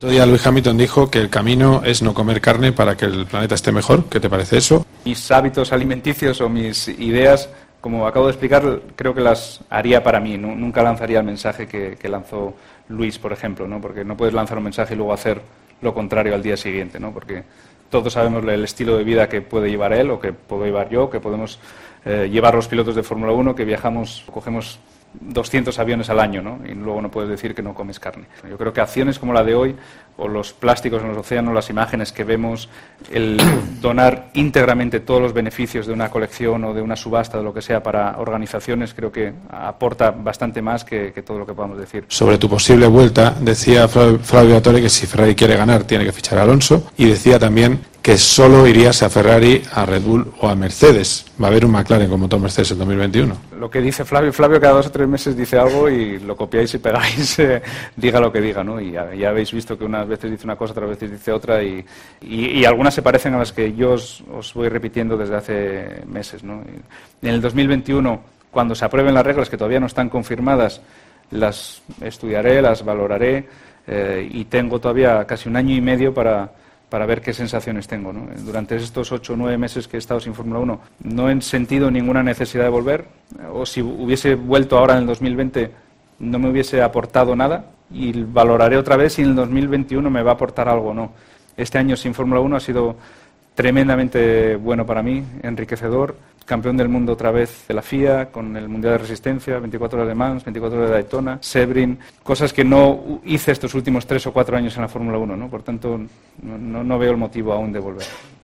charló con el doble campeón del Mundial de Fórmula Uno, tras presentar en Madrid dos iniciativas de su marca de ropa.